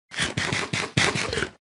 omnomnom.mp3